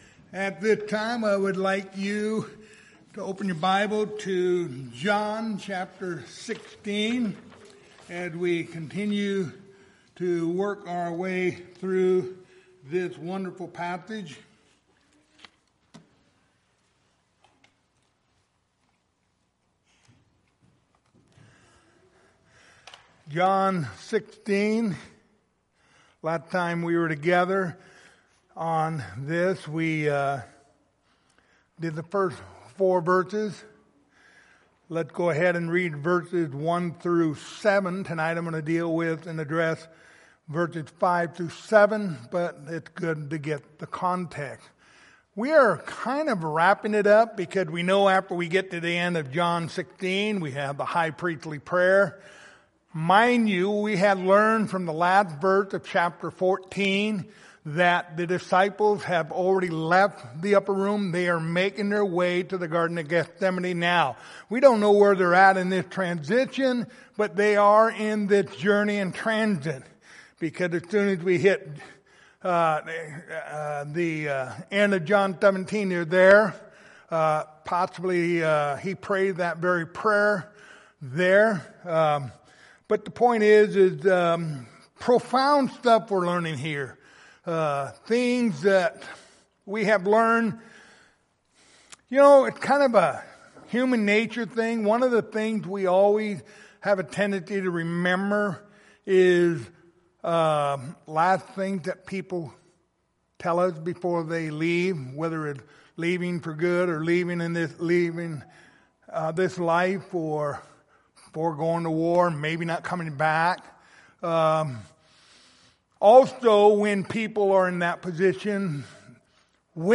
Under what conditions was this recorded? John 16:5-7 Service Type: Wednesday Evening Topics